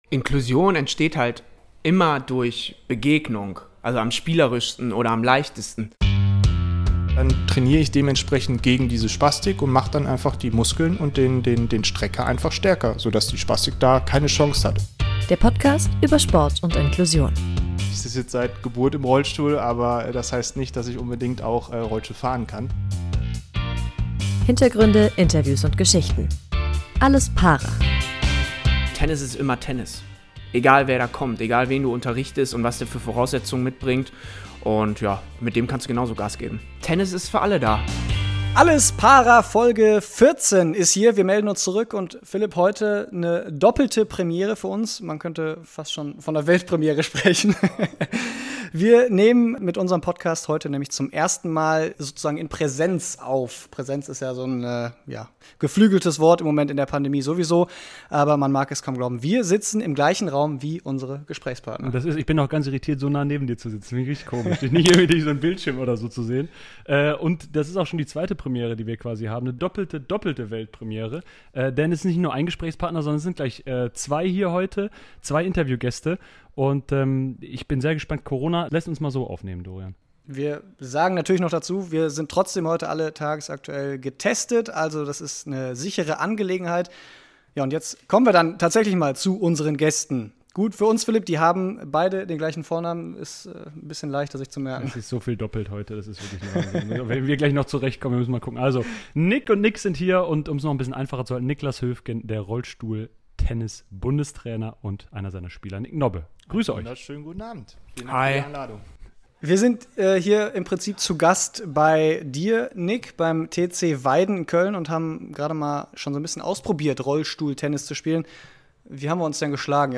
Beschreibung vor 4 Jahren "Alles Para?" feiert Premiere - die erste Podcast-Folge in Präsenz und dann gleich mit zwei Gästen.